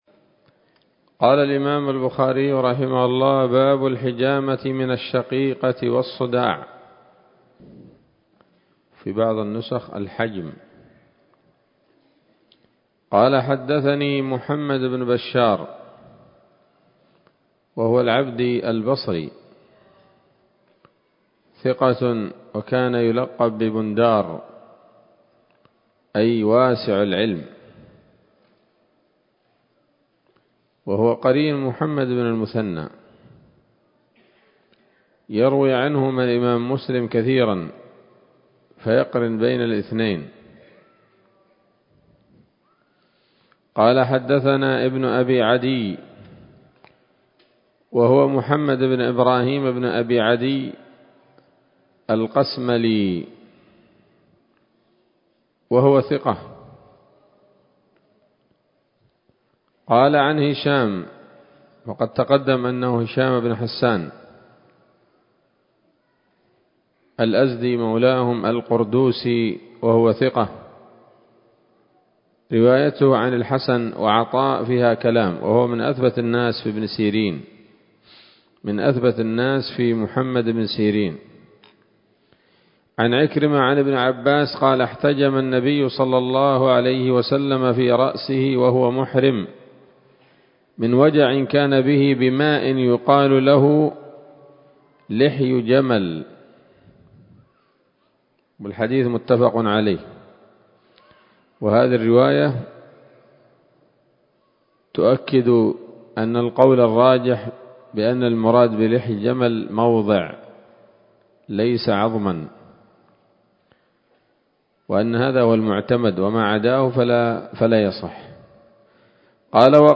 الدرس الثاني عشر من كتاب الطب من صحيح الإمام البخاري